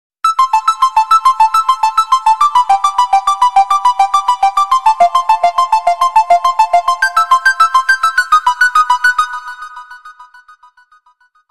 Iphone_alarm